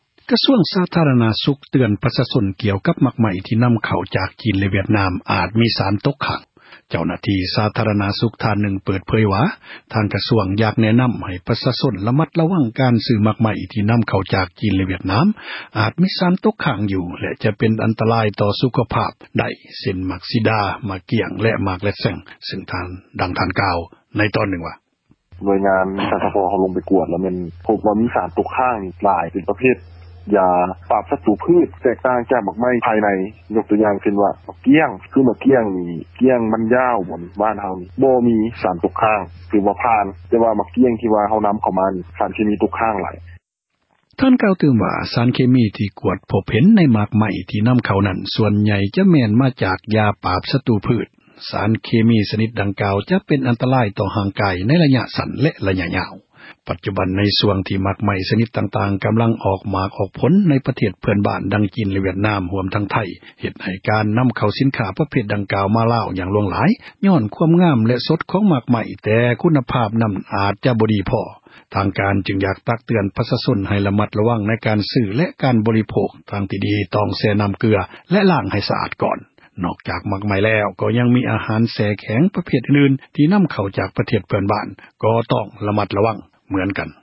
ເຈົ້າຫນ້າທີ່ ສາທາຣະນະສຸຂ ເປີດເຜີຍ ວ່າ ທາງ ກະຊວງ ຂໍແນະນຳ ປະຊາຊົນ ໃຫ້ ຣະມັດ ຣະວັງ ວ່າ ໝາກໄມ້ ທີ່ ມາຈາກ ຈີນ ແລະ ວຽດນາມ ອາດມີ ສານ ຕົກຄ້າງ ຊຶ່ງ ເປັນ ອັນຕຣາຍ ຕໍ່ ສຸຂພາບ ໄດ້ ເຊັ່ນ: ໝາກສີດາ ໝາກກ້ຽງ ແລະ ໝາກ ເຣັດແຊ໊ງ. ດັ່ງ ທ່ານ ກ່າວວ່າ: